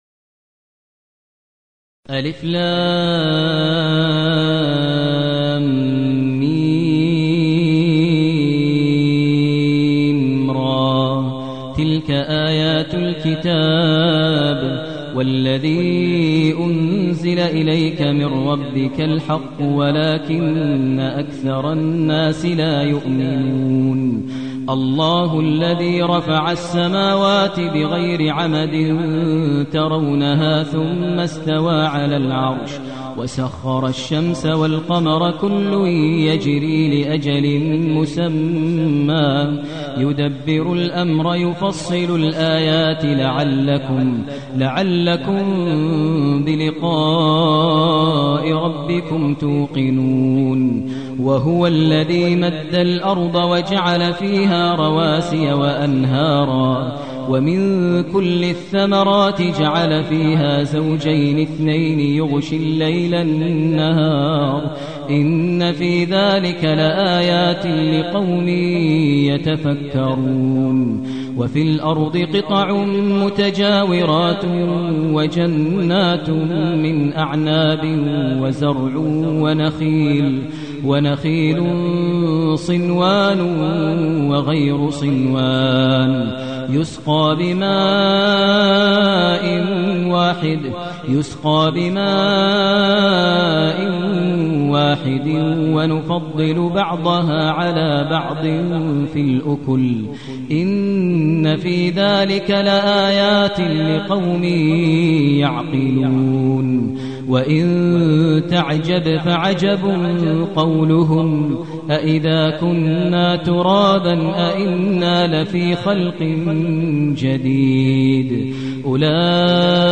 المكان: المسجد النبوي الشيخ: فضيلة الشيخ ماهر المعيقلي فضيلة الشيخ ماهر المعيقلي الرعد